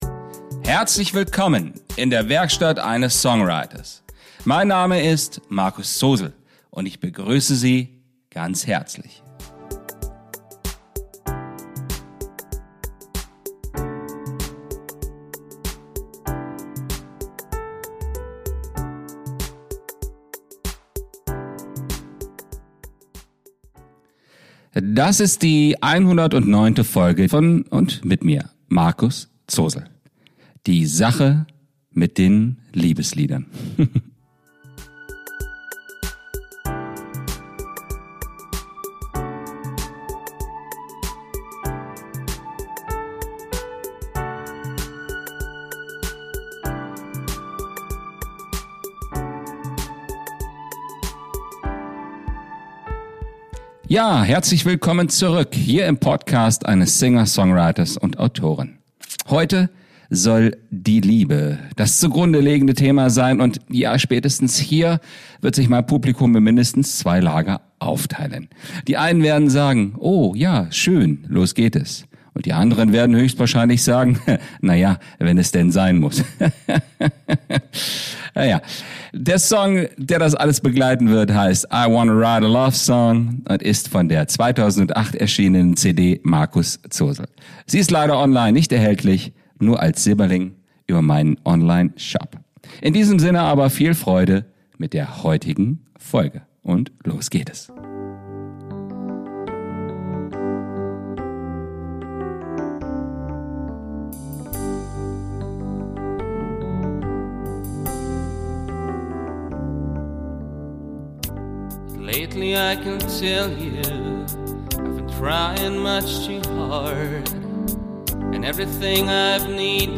In der heutigen Folge geht es um die Liebeslieder.... Begleitet von einem Liebeslied des Künstlers selbst